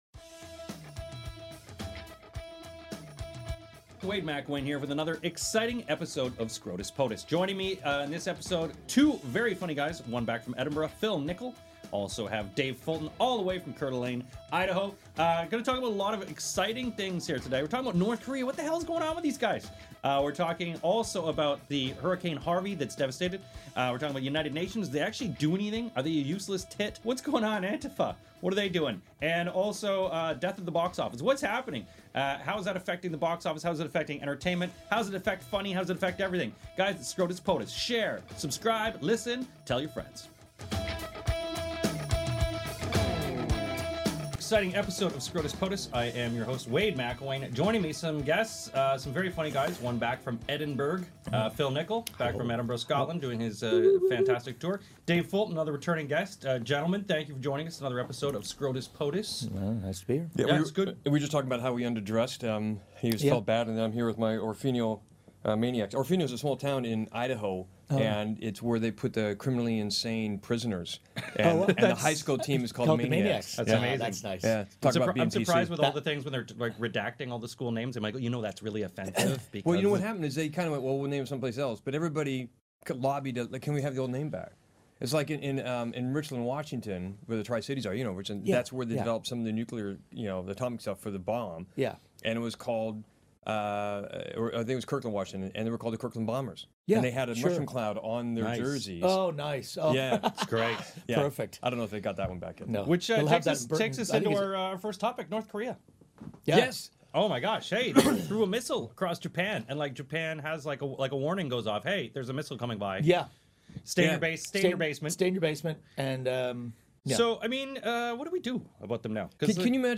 This is real life under our So Called Ruler of The United States. As told by comedians.